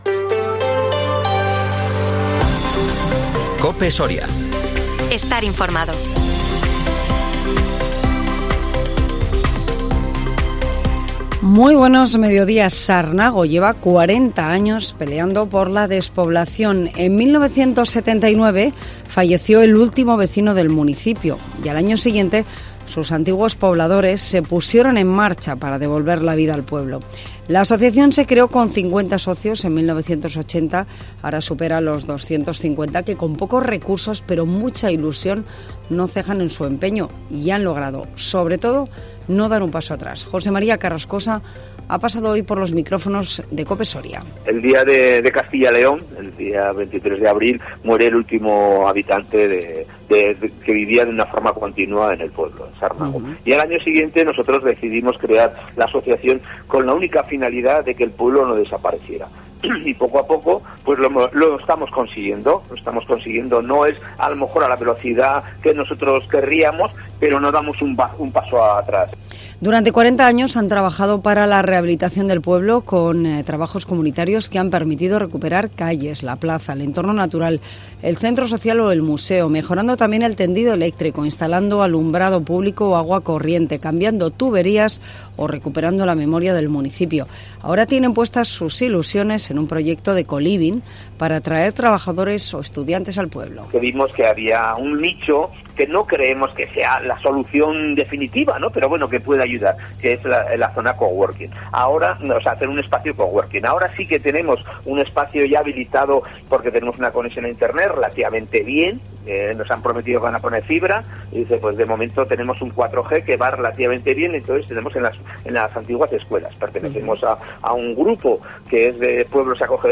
INFORMATIVO MEDIODÍA COPE SORIA LUNES 9 OCTUBRE 2023